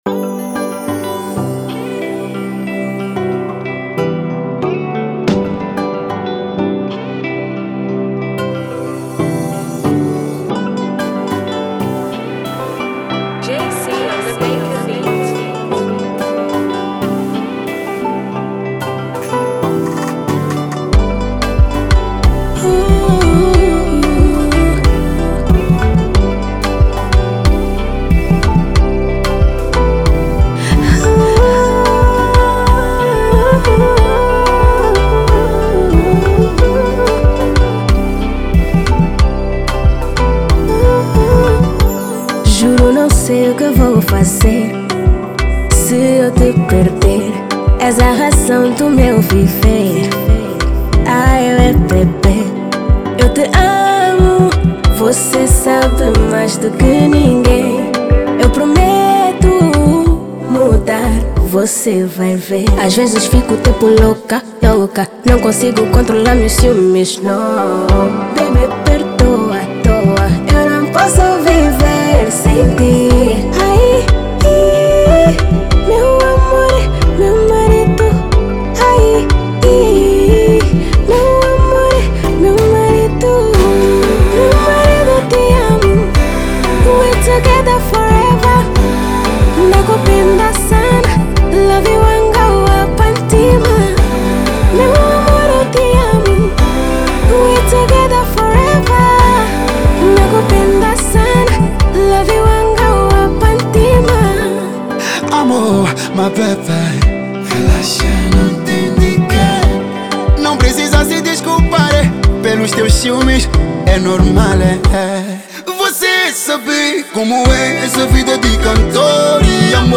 Kizomba
um tema com uma sonoridade kizomba